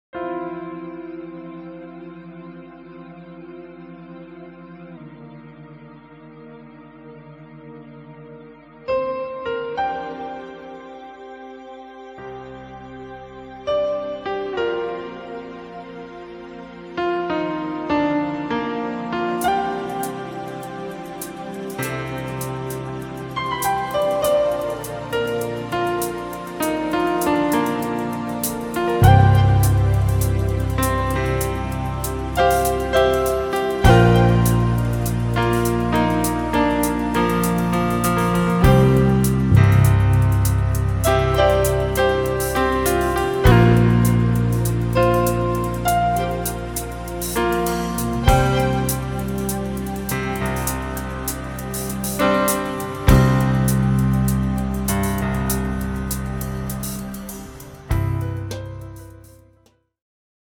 Experimental instrumental music